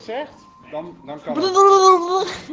blblblbl.mp3